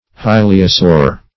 Hyleosaur \Hy"le*o*saur"\, n.